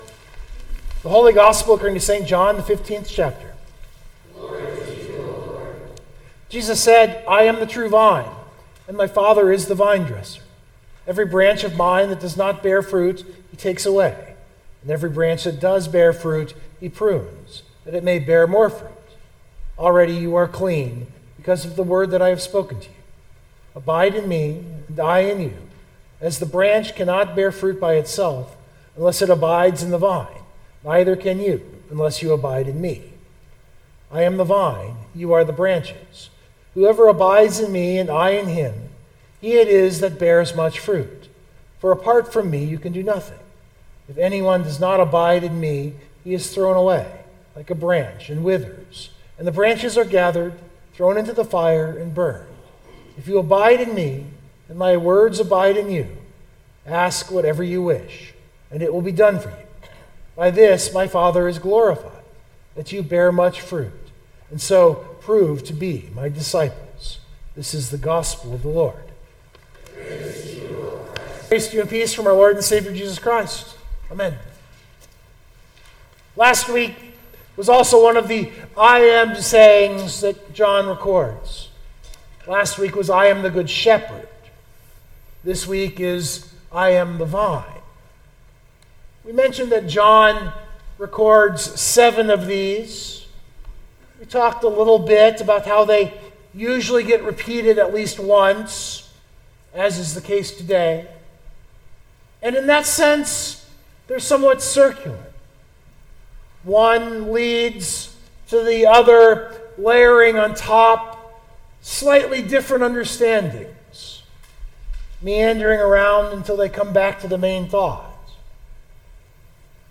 It was Palm Sunday also known as the Sunday of the Passion.
This sermon meditates on those two kingdoms.